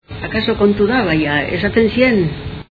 Dialectos
Salacenco